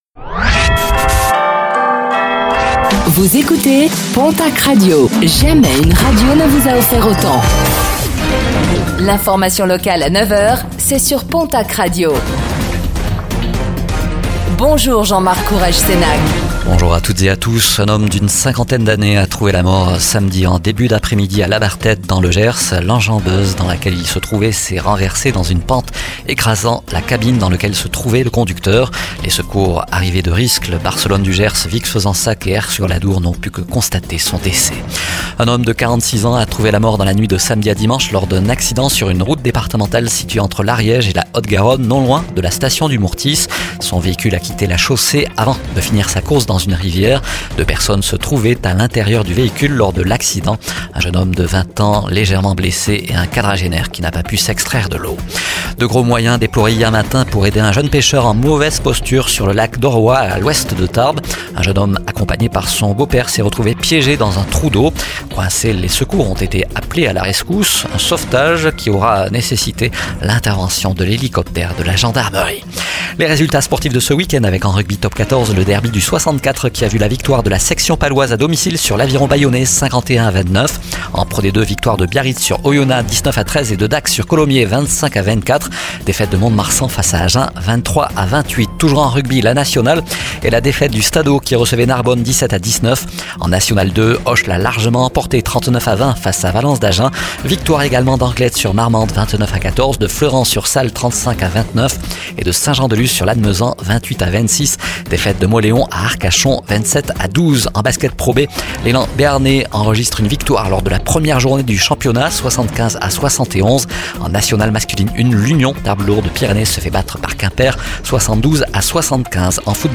Réécoutez le flash d'information locale de ce lundi 16 septembre 2024